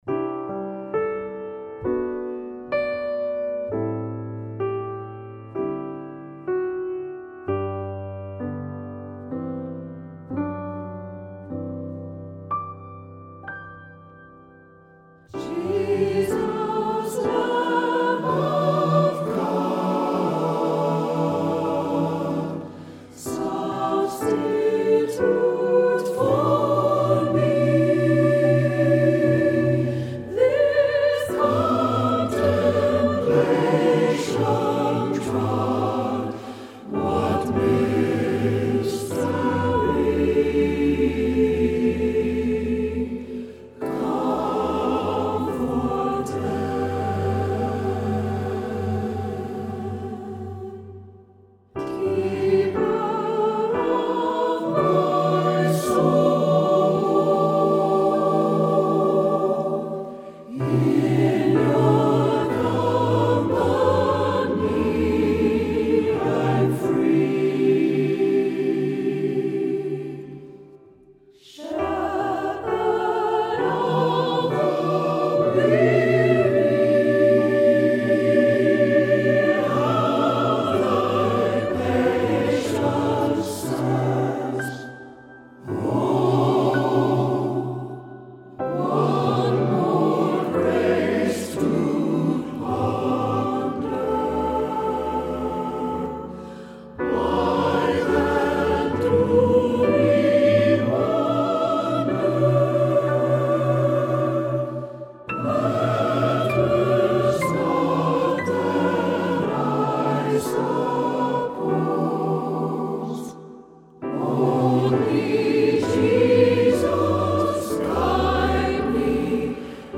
PIANO
UPRIGHT BASS